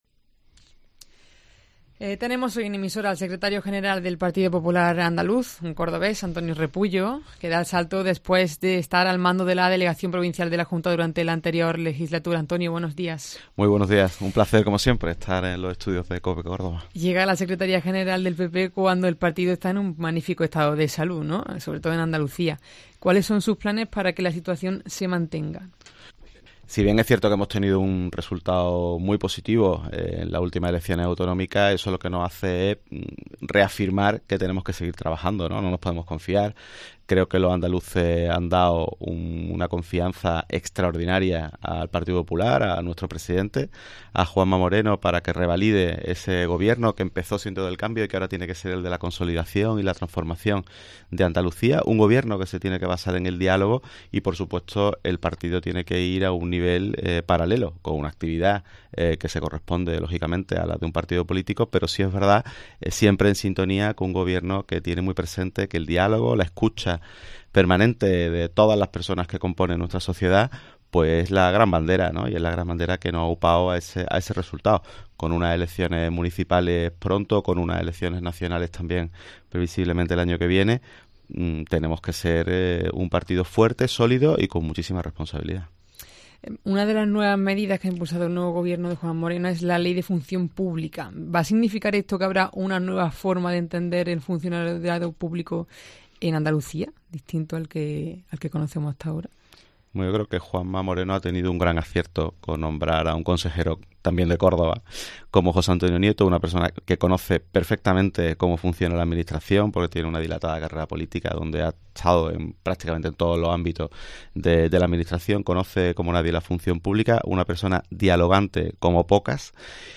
El nuevo secretario general del Partido Popular de Andalucía ha pasado por los micrófonos de COPE pocos días después de su nombramiento